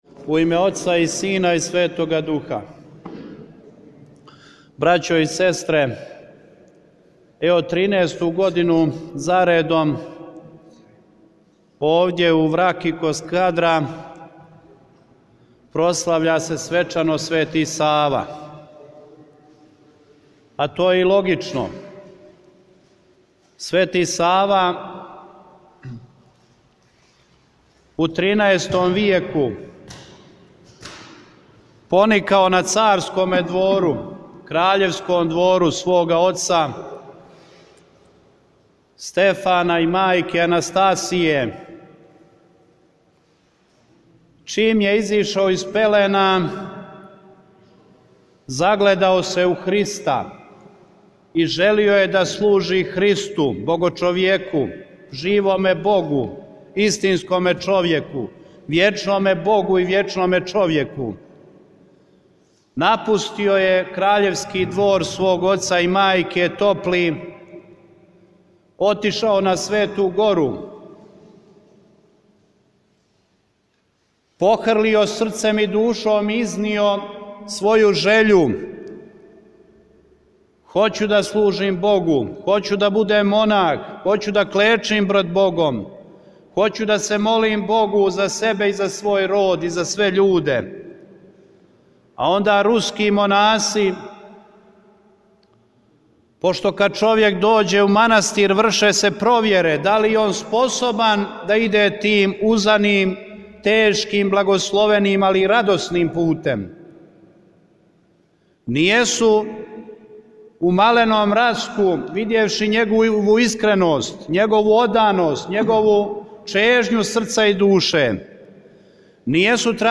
Са благословима Његовог блаженства архиепископа тиранског и цијеле Албаније г. др Анастасија Јанулатоса и Његовог Преосвештенства Епископа будимљанско-никшићког и администратора Митрополије црногорско-приморске г. Јоаникија, у цркви Пресвете Тројице у Враки код Скадра, свечано је прослављен празник Светог Саве, првог архиепископа и просветитеља српског.